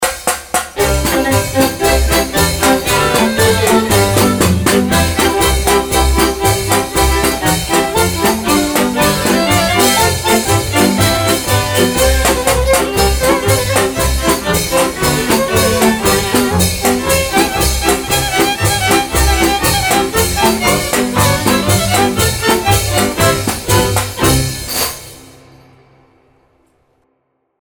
Nahrávací studio v Lipově audio / digital
Nahrávka bude použita na regionálním CD (zřejmě Uherskobrodský region), které bude mapovat folklorní zvyklosti tohoto kraje. Nahrávání bylo veselé, jak jinak s cimbálovou muzikou a ženským pěveckým sborem, a opět poučné.
Zajímavostí nahrávacího dne byla jistě písnička, která byla doprovázena ozemšvácem.